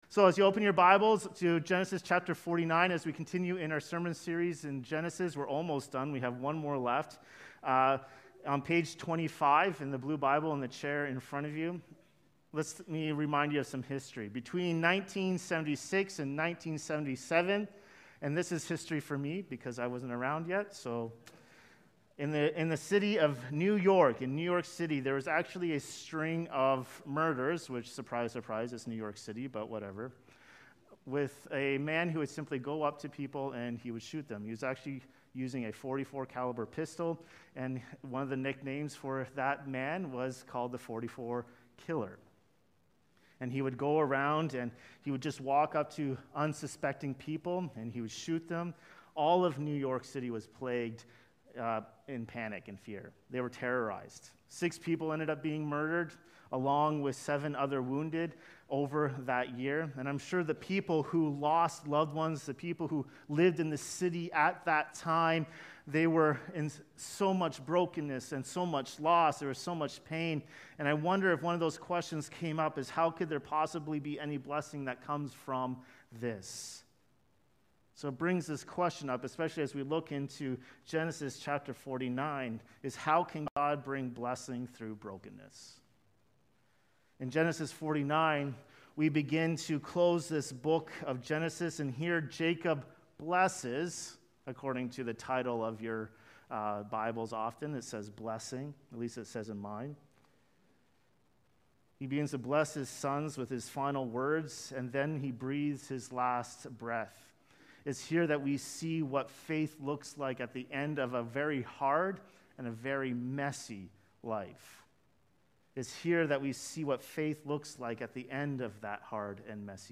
In Genesis 49, Jacob speaks words over his sons that reveal not just their past failures, but God’s future purposes. This sermon explores how God brings blessing out of brokenness—not because of human merit, but through the promised Messiah.